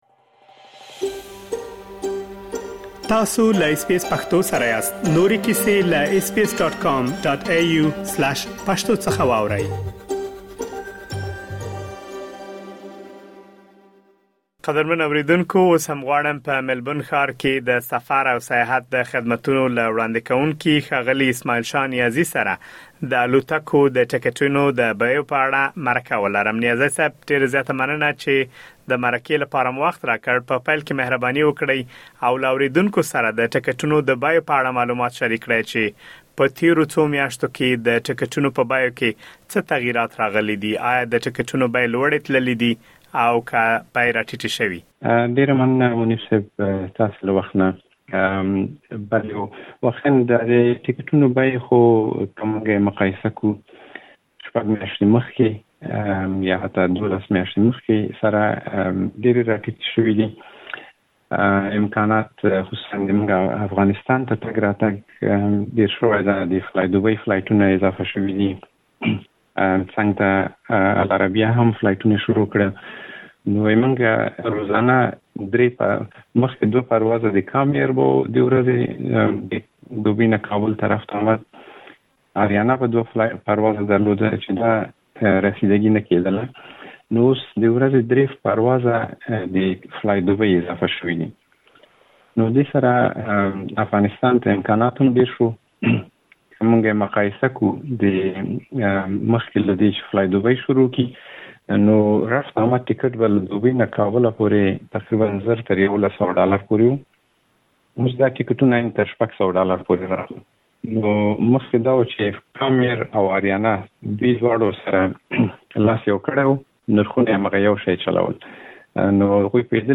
مرکه ترسره کړې.